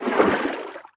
Sfx_creature_babypenguin_swim_barrel_roll_02.ogg